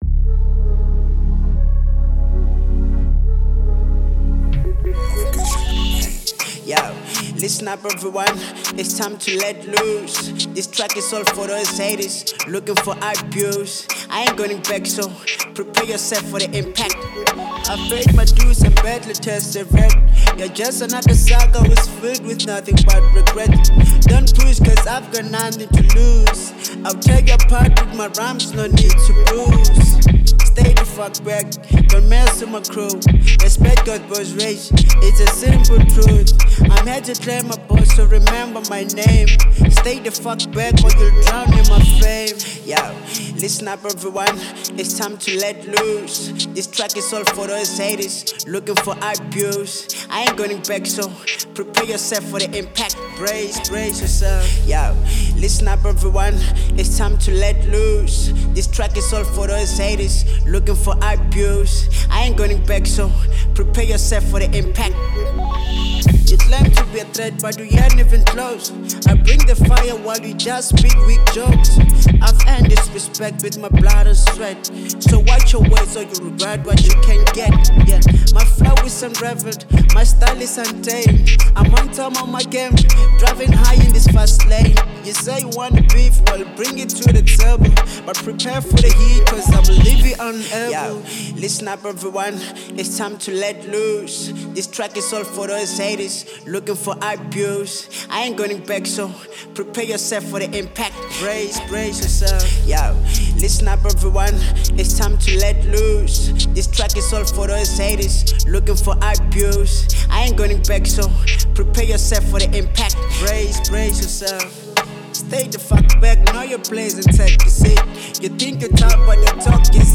02:42 Genre : Hip Hop Size